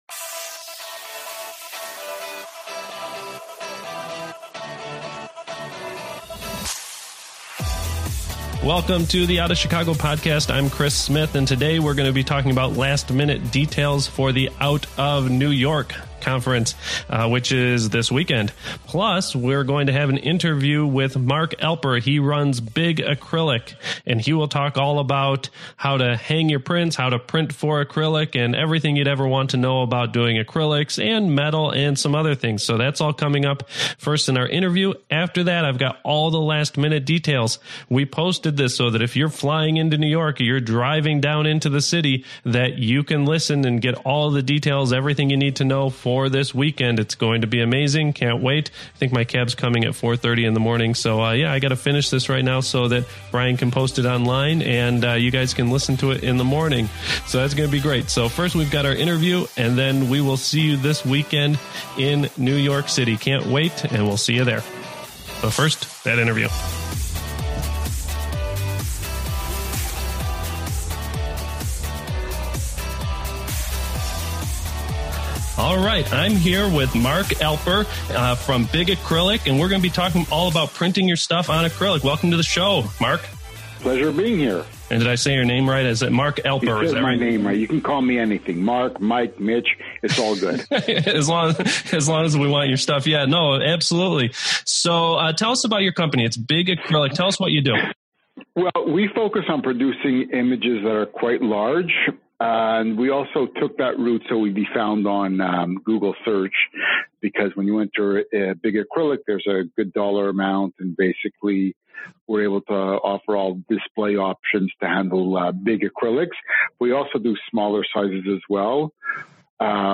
Following the interview